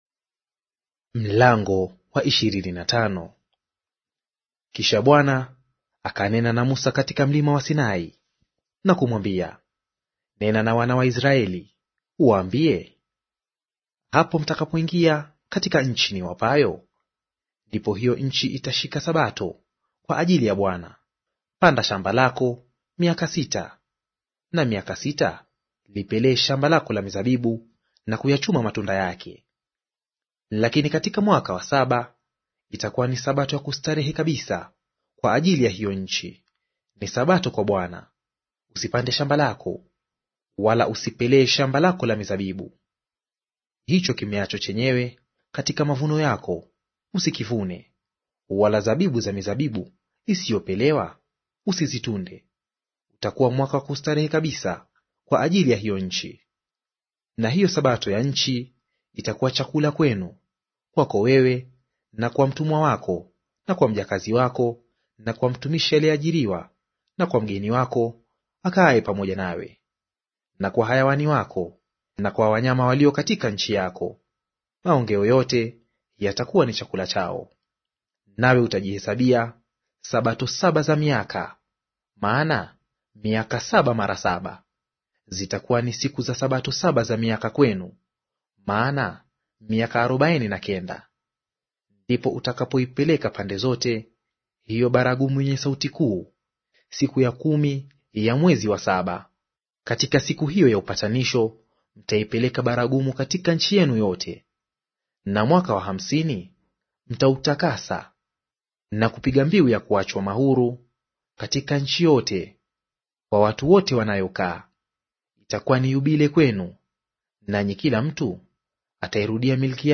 Audio reading of Mambo ya Walawi Chapter 25 in Swahili